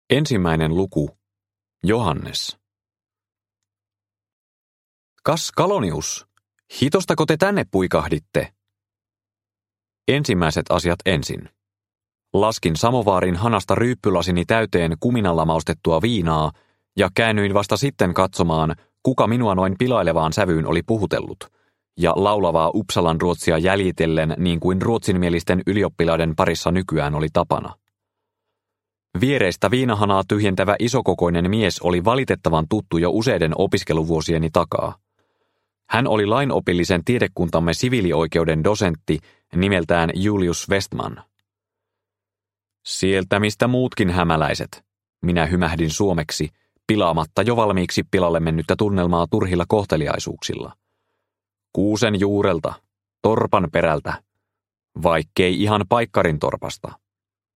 Kuolema ylioppilastalolla – Ljudbok – Laddas ner